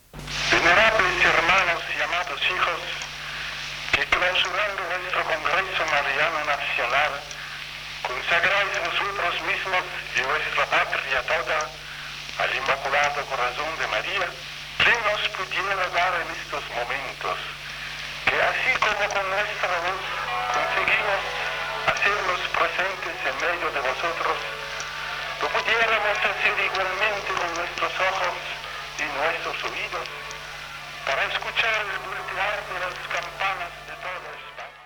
Paraules del Sant Pare Pius XII en la cloenda del Congreso Internacional de Congregaciones Marianas en España celebrat a Barcelona (del 29 de novembre al 10 de desembre del 1947)